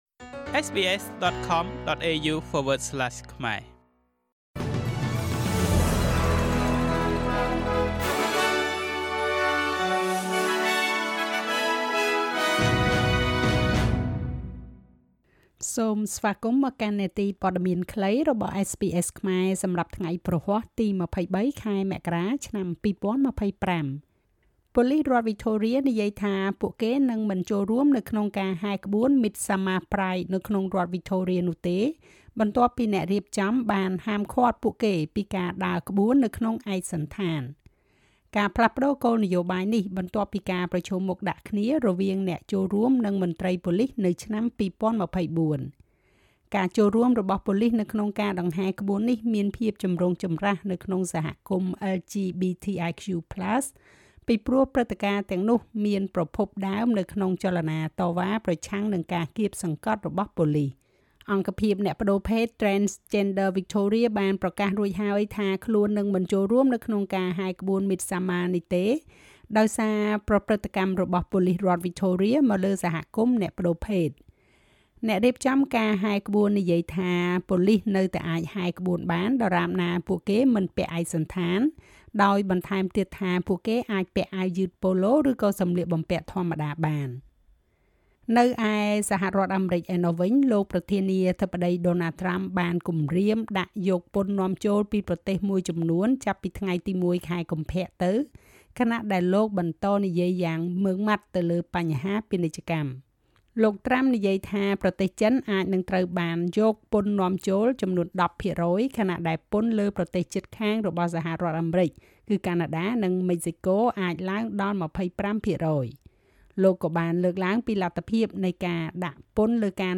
នាទីព័ត៌មានខ្លីរបស់SBSខ្មែរ សម្រាប់ថ្ងៃព្រហស្បតិ៍ ទី២៣ ខែមករា ឆ្នាំ២០២៥